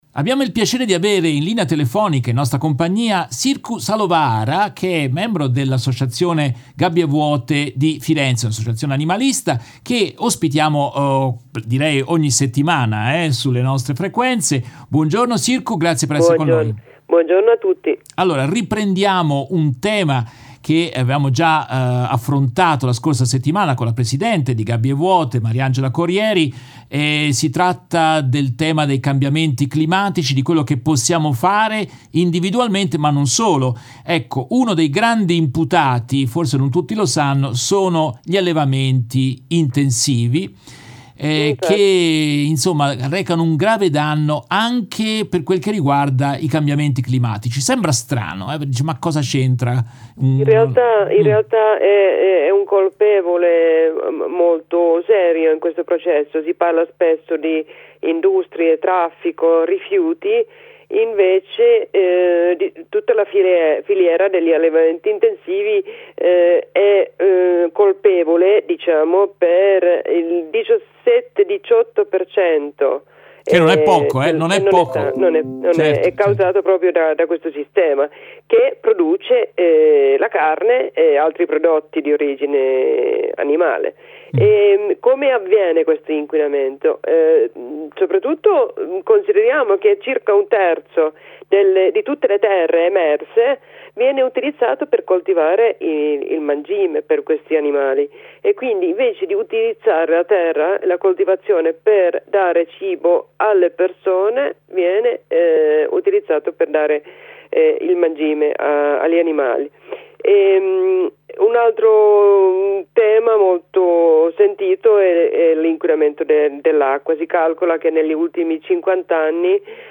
In questa intervista tratta dalla diretta RVS del 15 ottobre 2021